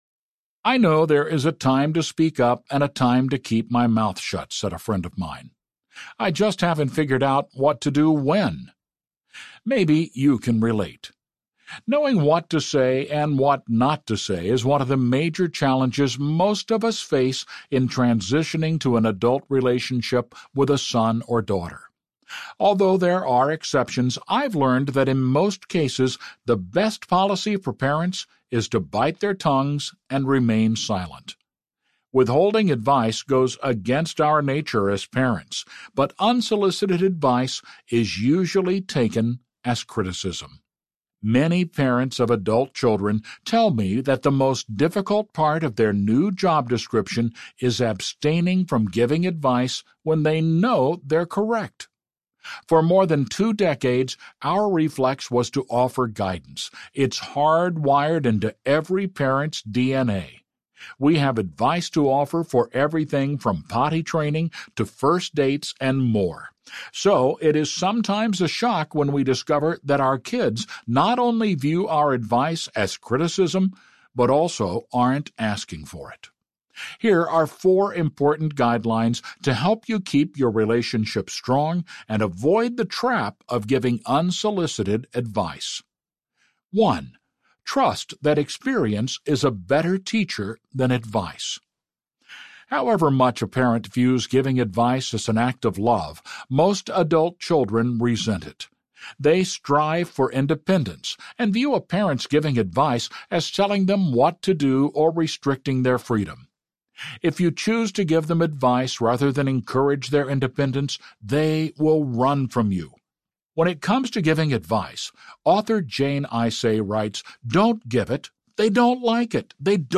Doing Life with Your Adult Children Audiobook
Narrator